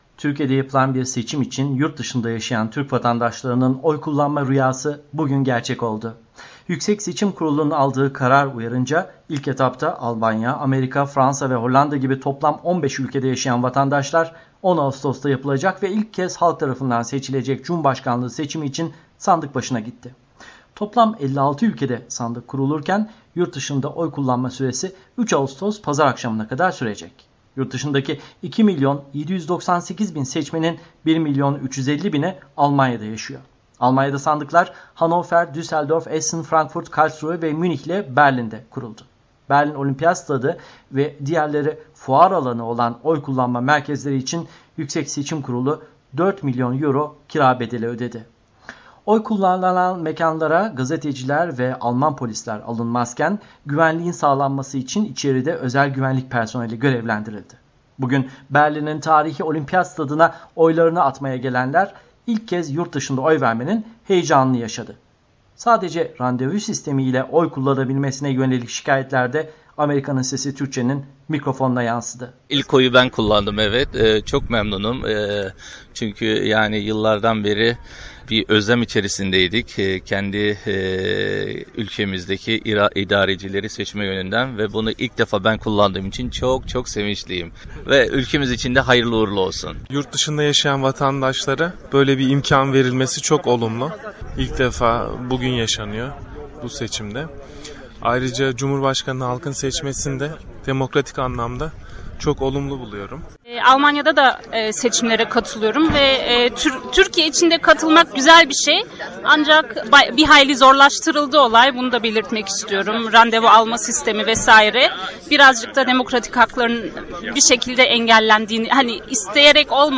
Bugün Berlin’in tarihi Olimpiyat Stadı’na oylarını atmaya gelenler, ilk kez yurtdışında oy vermenin heyecanını yaşadı. Sadece randevu sistemi ile oy kullanılabilmesine yönelik şikayetler de Amerika’nın Sesi Türkçe’nin mikrofonuna yansıdı.